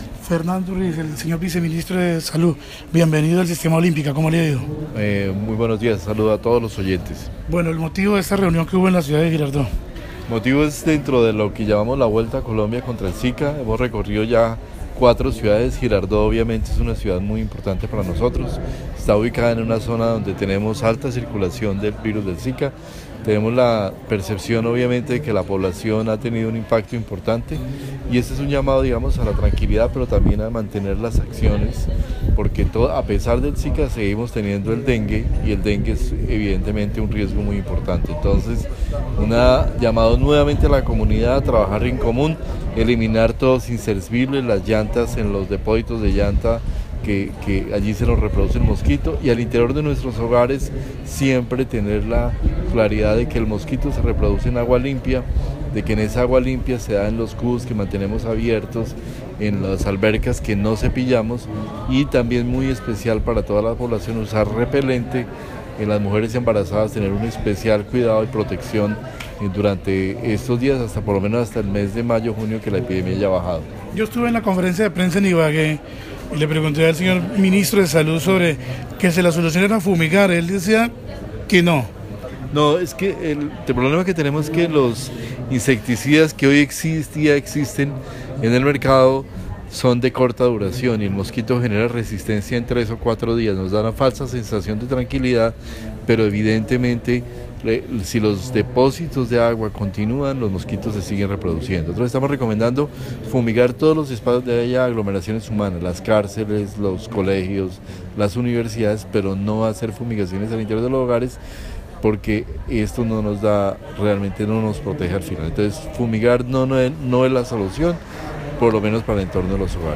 Audio: Vicesalud hace pedagogía sobre Zika en Girardot